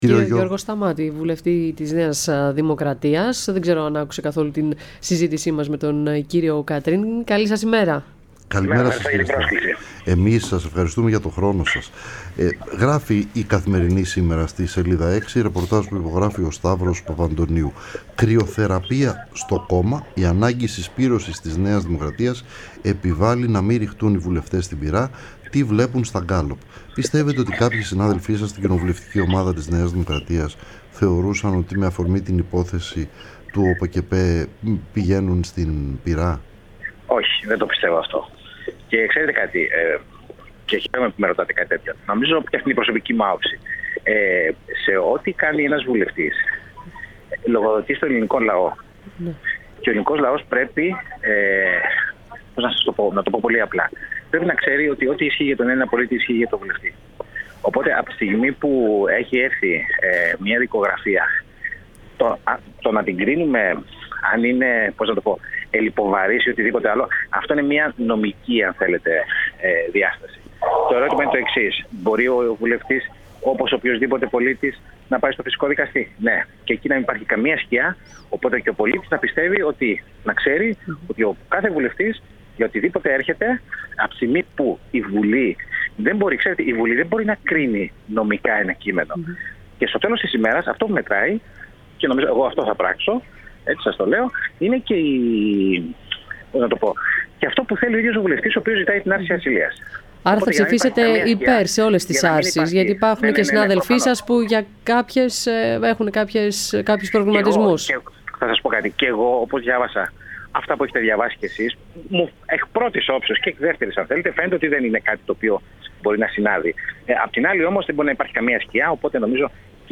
Ο Γιώργος Σταμάτης, βουλευτής ΝΔ, μίλησε στην εκπομπή “Ραντάρ”